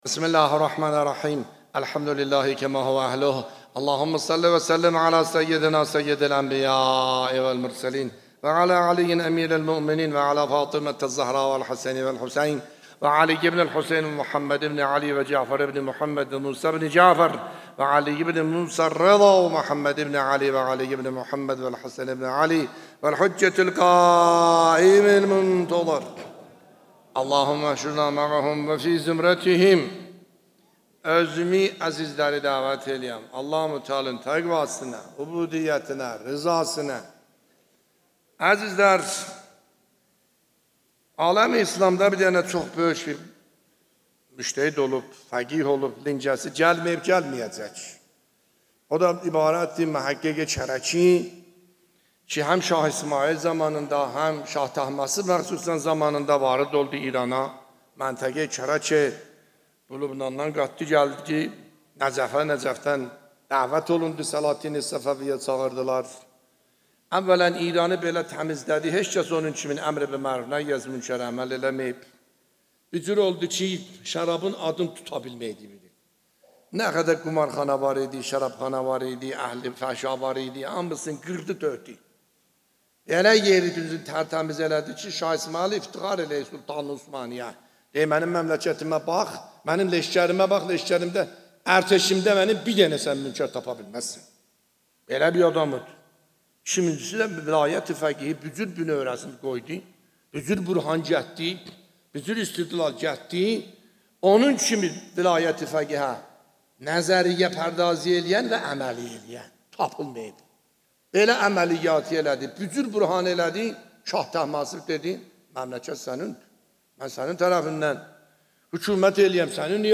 خطبه‌ های نماز جمعه اردبیل | آیت الله عاملی (23 تیر 1402) + متن
بیانات آیت الله سید حسن عاملی نماینده ولی فقیه و امام جمعه اردبیل در خطبه های نماز جمعه در 23 تیر 1402